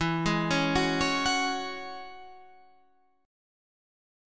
E6add9 chord